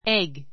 éɡ エ グ